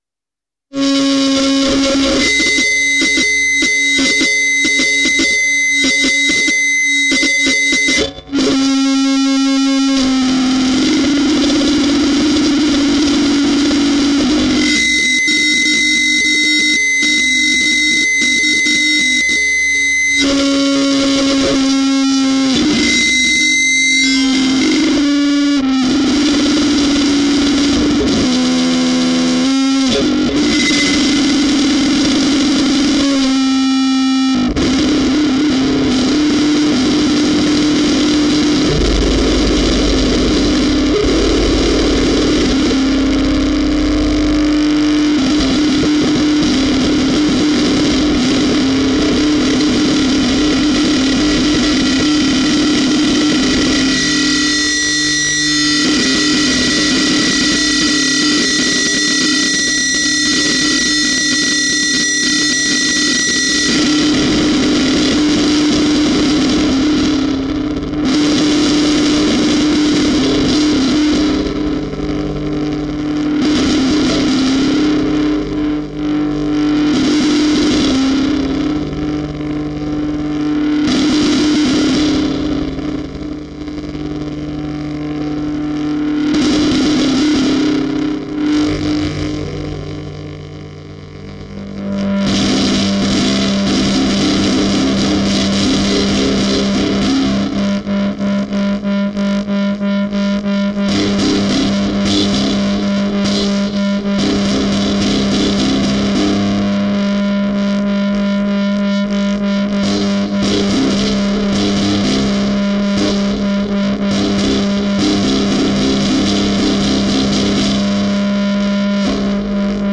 Experimental Music
Music for guitar with magnet on pickup, e-bow and fuzz 3 added.
0014musicforguitarwithmagnetonpickupebowandfuzz3.mp3